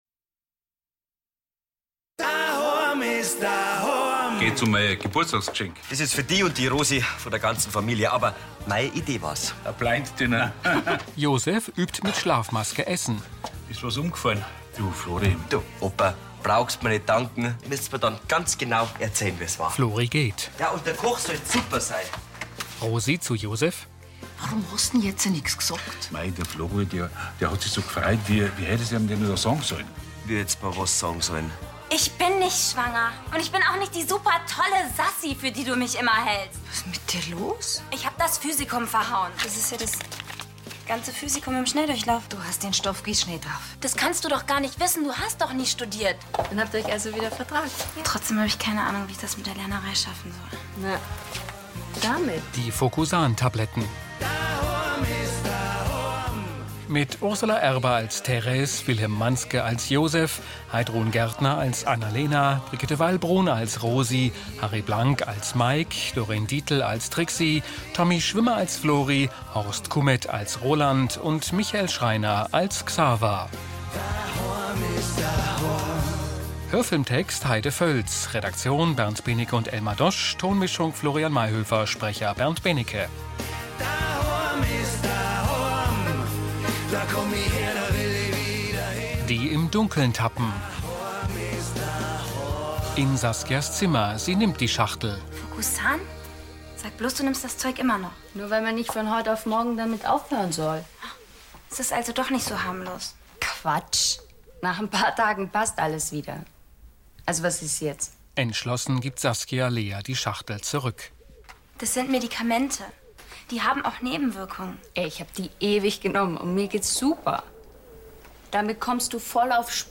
Beschreibung: Die bayerische Daily "Dahoam is Dahoam" mit Audiodeskription - ab April 2013 ist es soweit. Als kleines Weihnachtsgeschenk können Sie hier schon mal reinhören, wie sich die Folge 1003 vom Oktober mit Audiodeskription anhört!